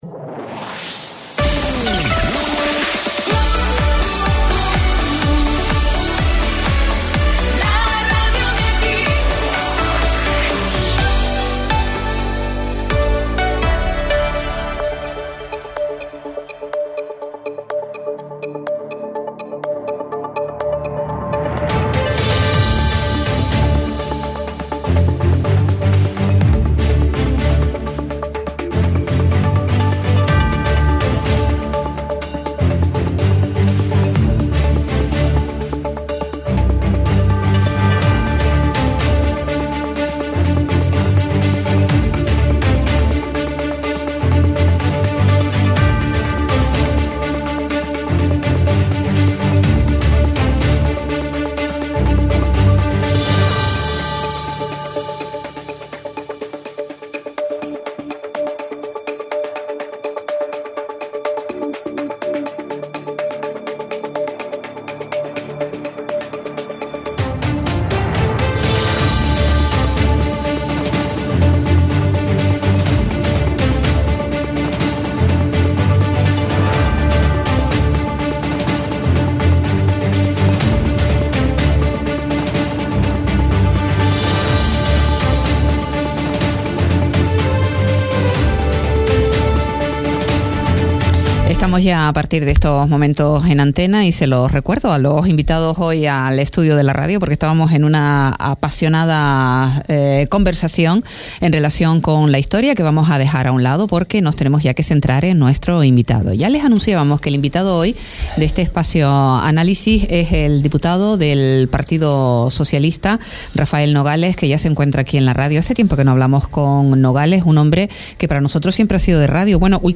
El diputado del Partido Socialista Rafael Nogales participa hoy en el espacio Análisis de Radio Sintonía Fuerteventura.